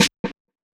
SNARE ECHO.wav